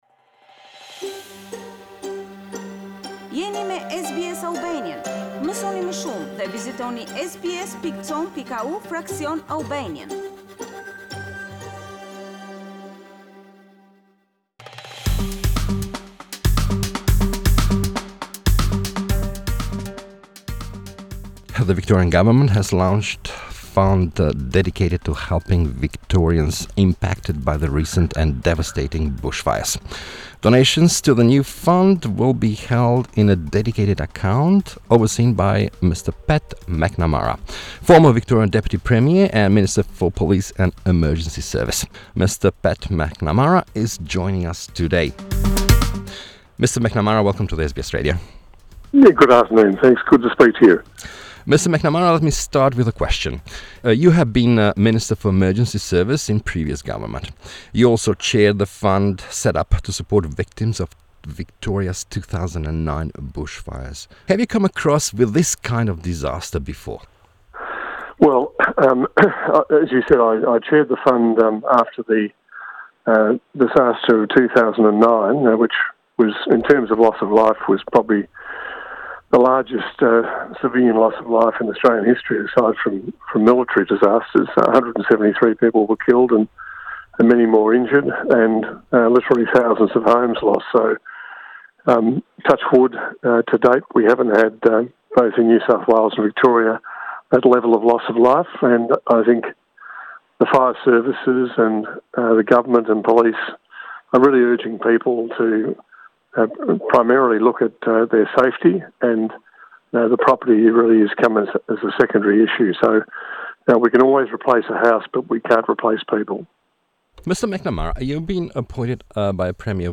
We spoke to Pat McNamara Chairperson of Victorian Bushfire Appeal.